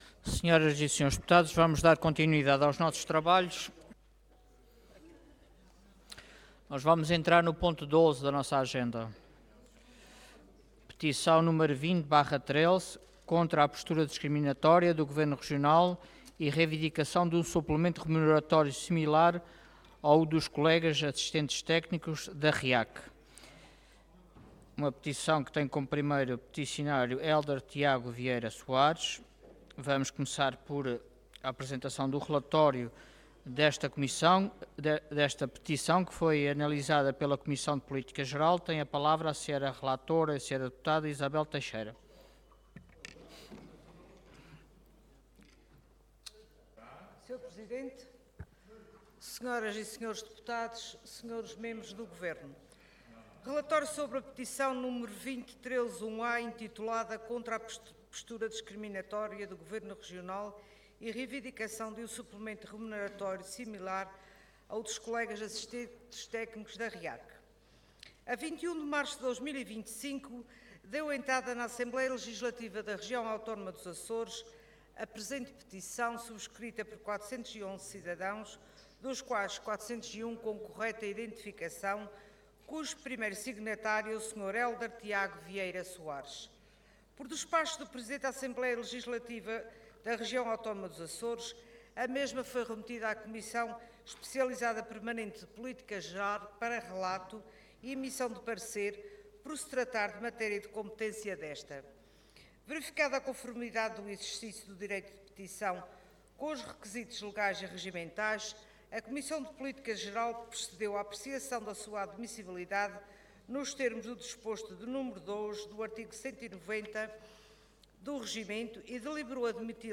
Intervenção